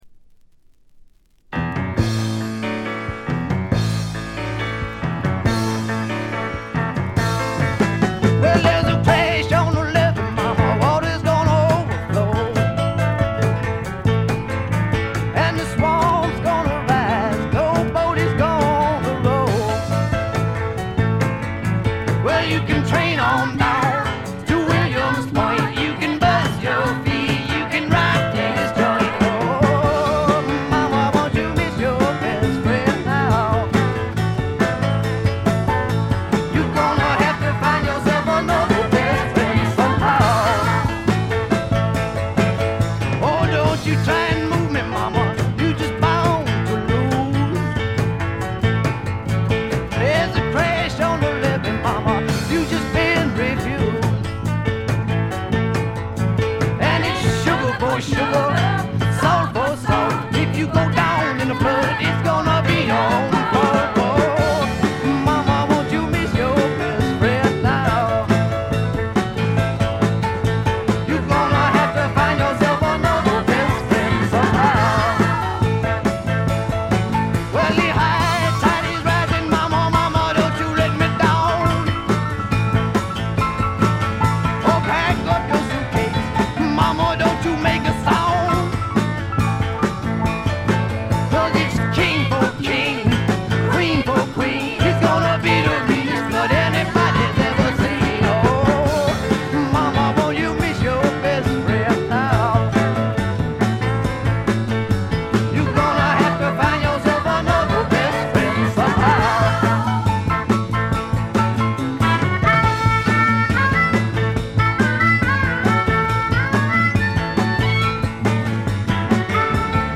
軽微なバックグラウンドノイズ、チリプチ程度。
試聴曲は現品からの取り込み音源です。